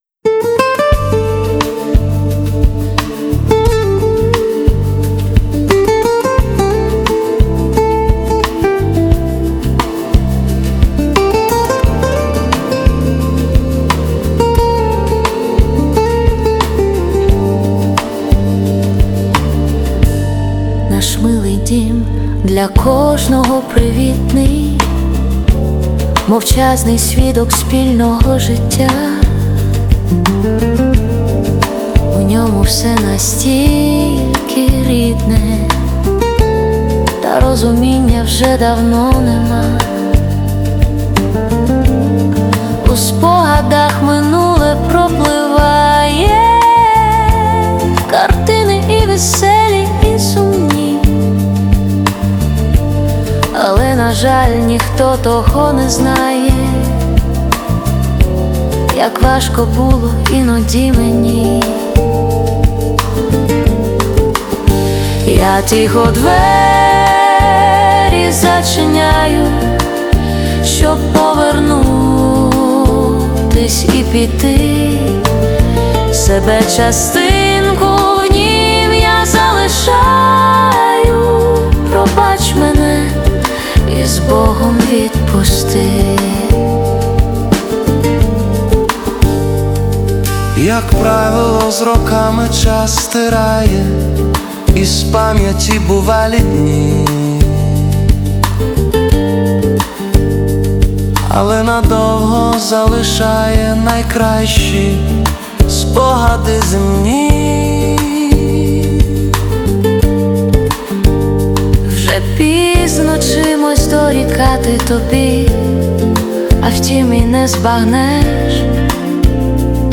Стиль: Фолк-поп